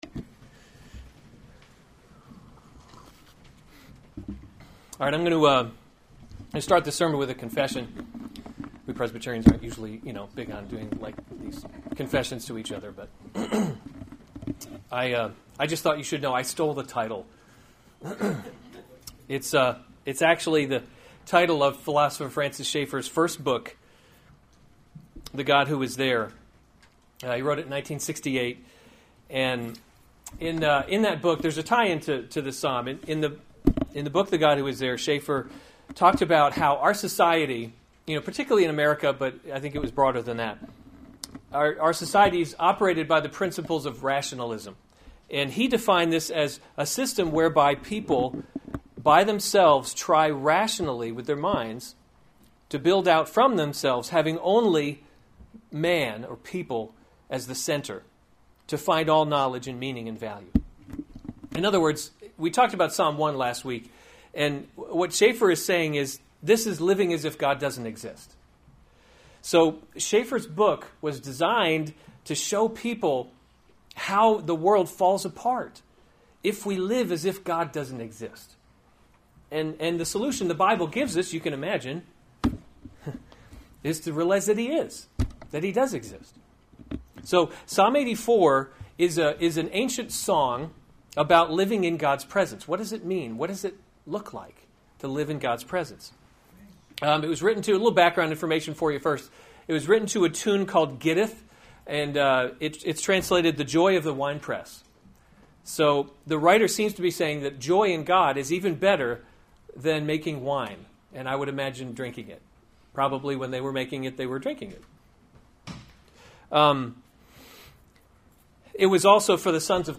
July 9, 2016 Psalms – Summer Series series Weekly Sunday Service Save/Download this sermon Psalm 84 Other sermons from Psalm My Soul Longs for the Courts of the Lord To […]